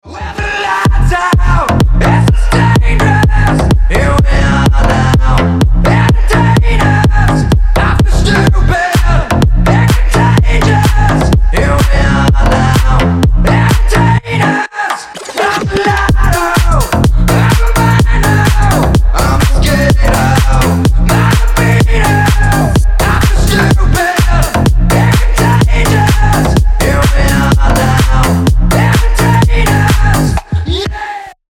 мужской голос
мощные
remix
Club House
качающие
slap house
Стиль: club house